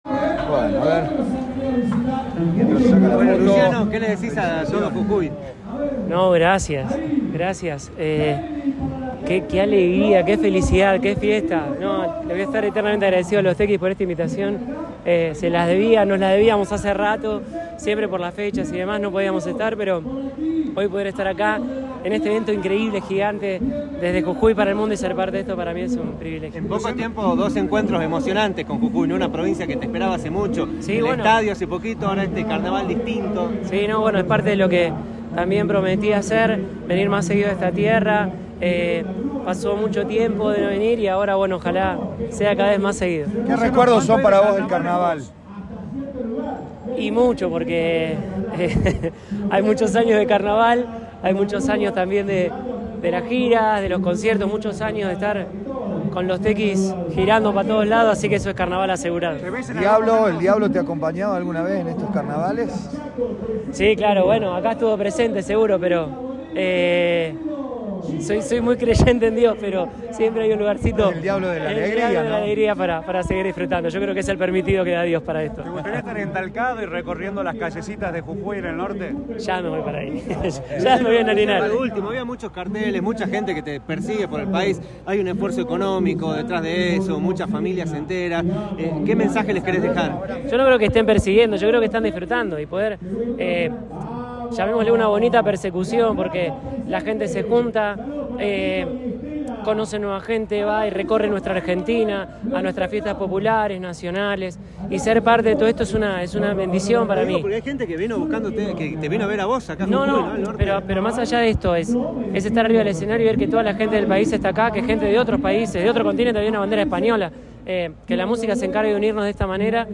Audio. Carnaval en altura con Pawhay, al ritmo de Los Tekis y Luciano Pereyra